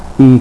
"y" is pronounced just like the Spanish "i"..
Click on the Spanish word to hear it pronounced.